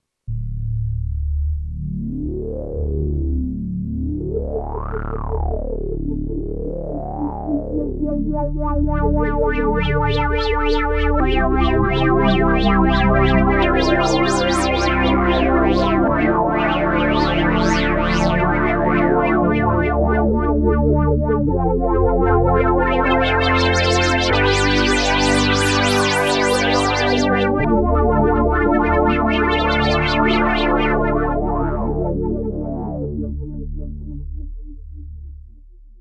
No effects were used, it's just a direct feed from the stereo analogue outputs.
2) Lowpass filter with polymod -
This just because it's an old favorite of mine from my Prophet 5 days. 2 oscillators feeding the ladder-type Lowpass 24 dB filter, with medium resonance. The filter cutoff is modulated by 2 LFOs, one of which is tracking the keyboard, while the other a very slow sweep. A third input provides an envelope to the cutoff. Again a bit of Pan modulation by another LFO.